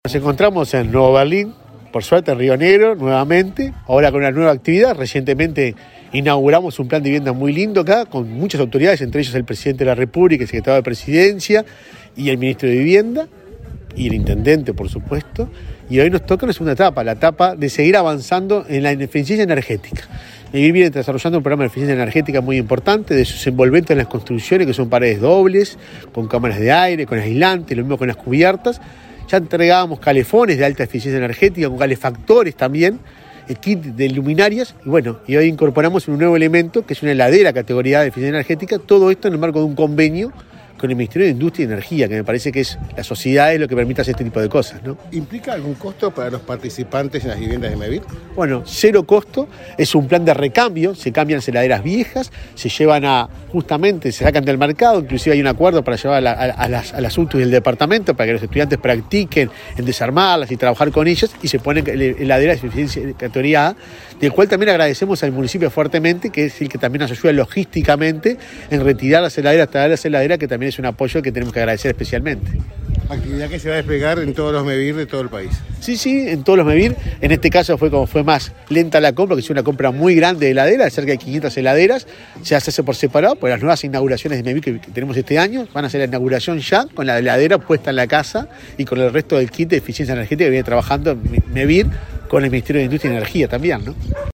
Declaraciones del presidente de MEVIR, Juan Pablo Delgado
El presidente de Mevir, Juan Pablo Delgado, dialogó con la prensa en Río Negro, antes de participar del acto de entrega de equipamiento eficiente a